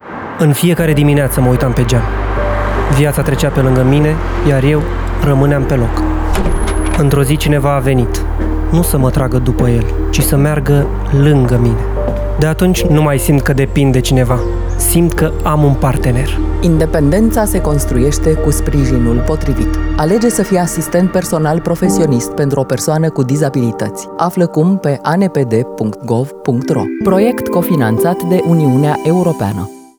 ANPDPD_spot-radio_final.wav